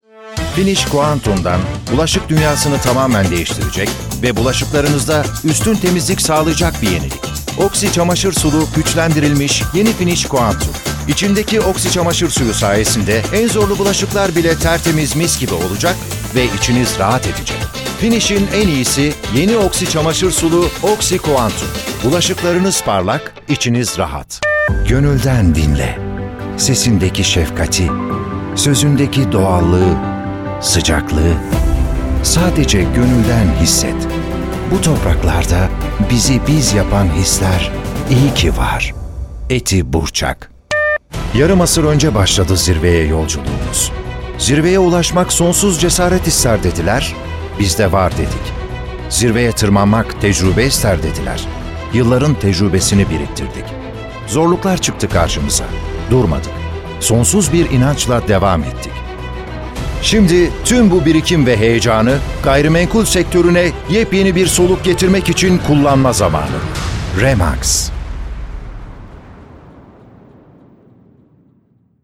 Reklam Filmi Seslendirme